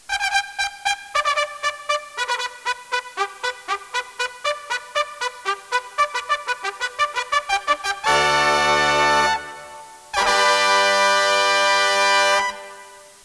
1 channel
fanfar12.wav